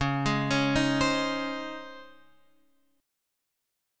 C# Major 7th Suspended 2nd